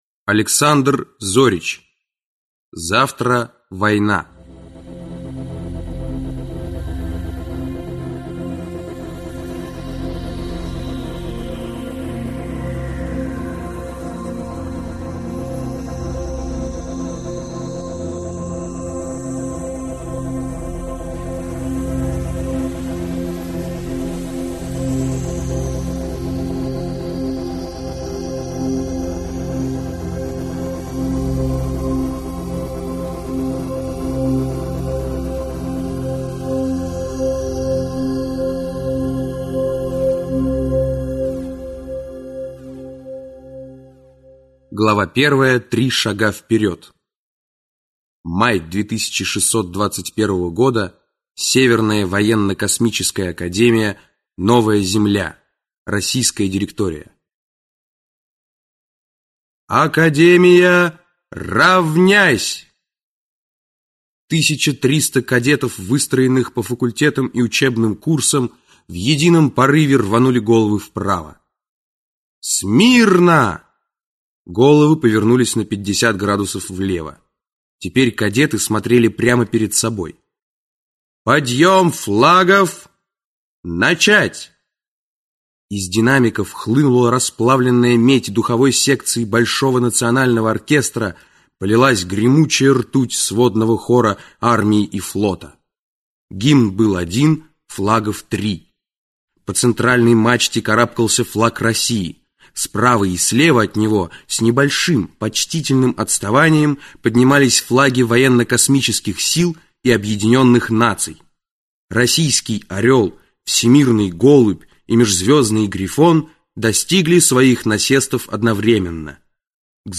Аудиокнига Завтра война | Библиотека аудиокниг